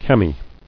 [cam·i]